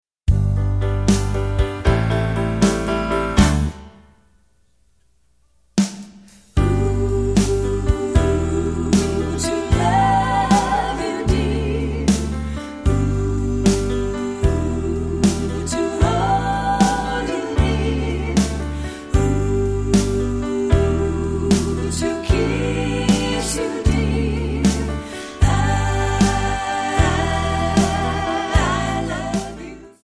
Backing track Karaoke
Pop, Oldies, 1950s